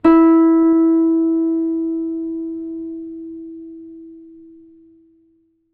ZITHER E 2.wav